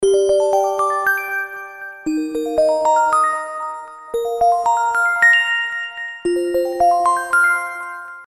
Рингтоны без слов , Спокойные рингтоны
Простые рингтоны , Мелодичные